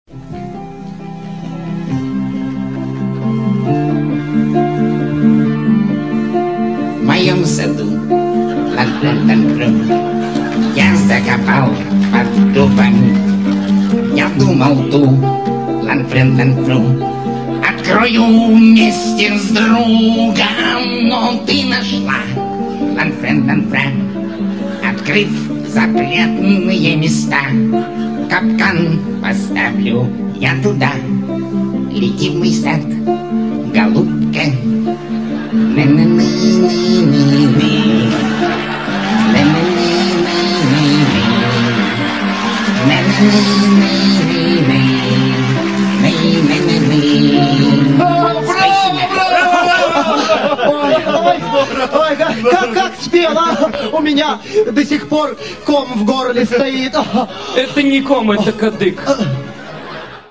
пародия